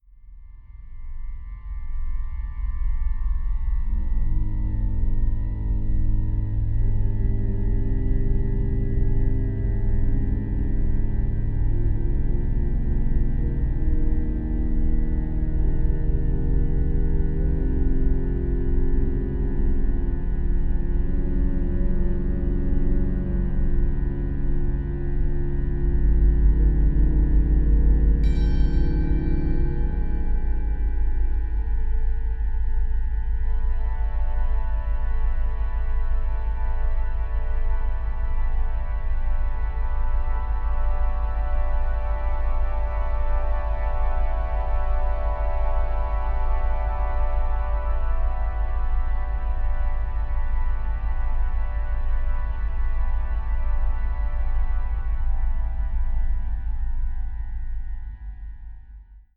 for grand organ